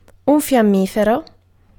Ääntäminen
IPA : /mætʃ/